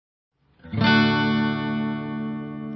D大调
描述：D主要声学和弦
Tag: 和弦 d 和弦